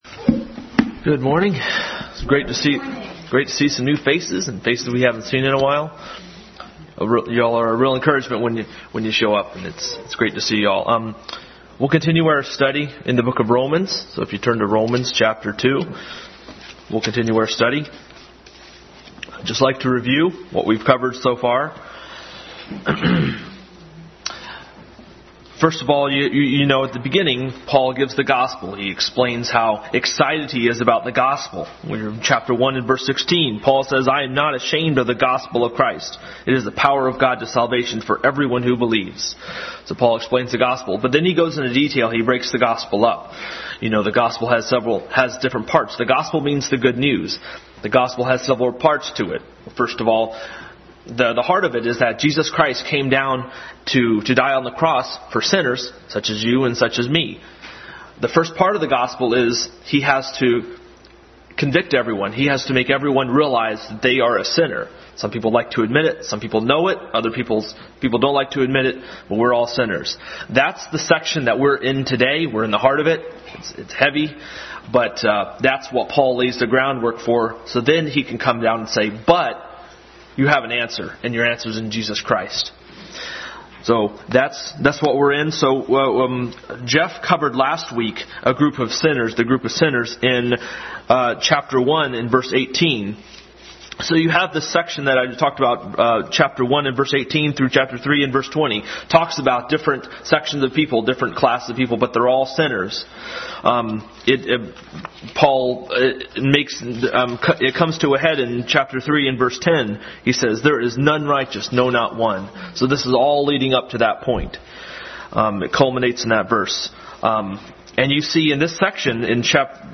Adult Sunday School Class. Continued study in the book of Romans.